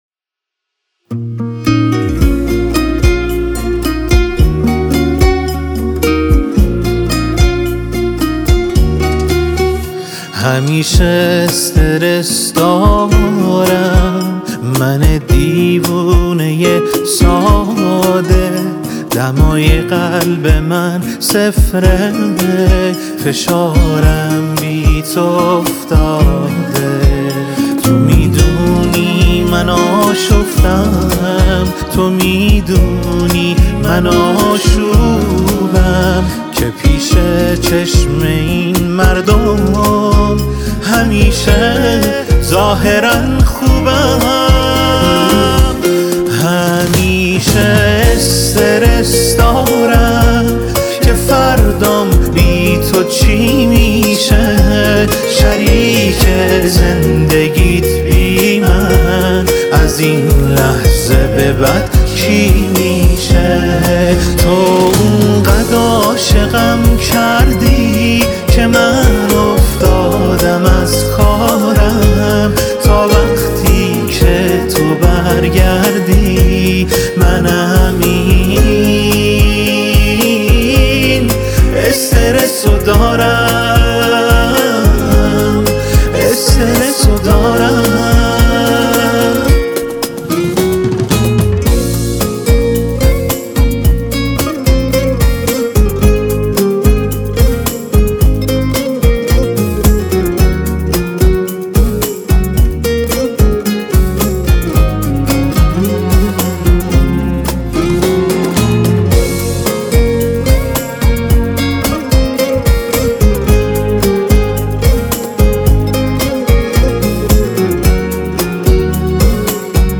ورژن انپلاگ